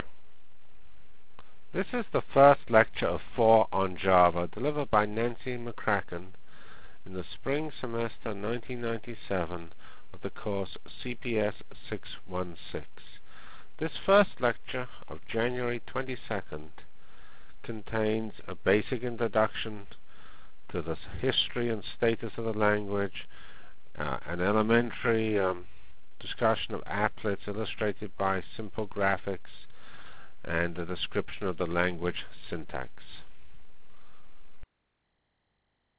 Foil 1 CPS 616 Java Lectures